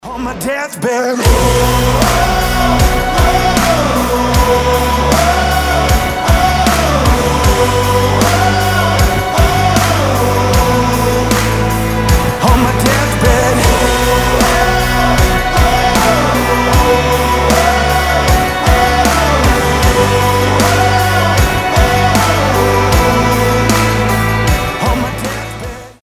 • Качество: 320, Stereo
громкие
брутальные
Alternative Rock